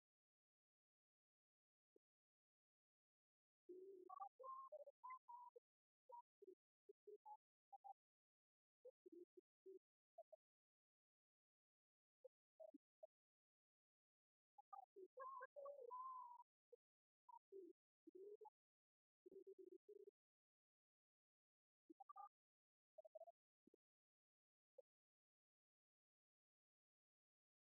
musique varieté, musichall
Genre strophique
Repas annuel pour les retraités
Pièce musicale inédite